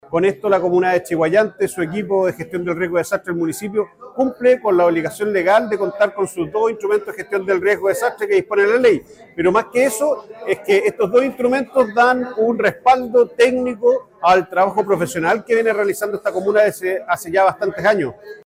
En tanto, Alejandro Sandoval, director regional Senapred manifestó su alegría por esta aprobación.